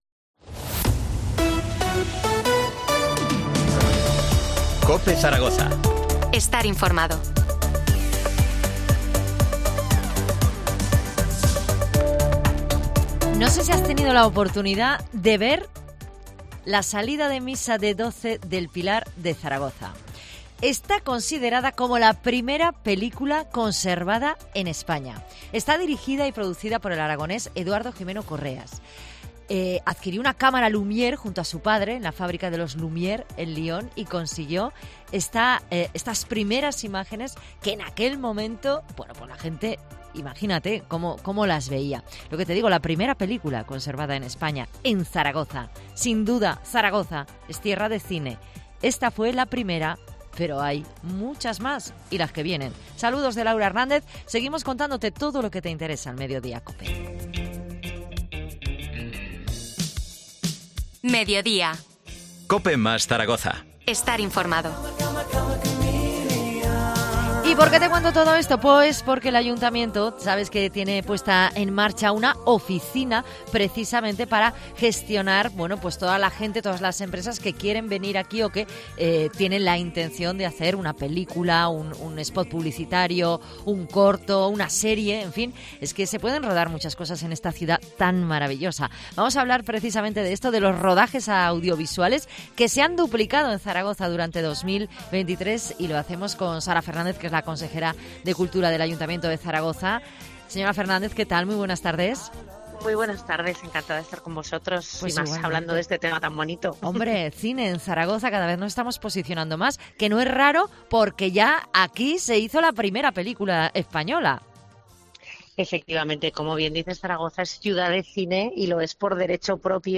Entrevista a la consejera de Cultura, Sara Fernández, sobre los rodajes de cine en Zaragoza